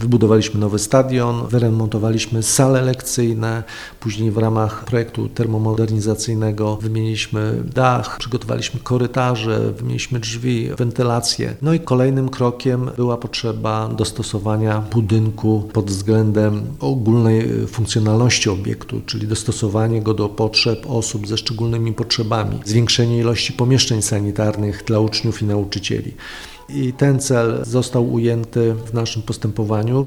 – Wcześniej wykonaliśmy wiele prac inwestycyjnych – powiedział Waldemar Wrześniak, nowosolski wicestarosta: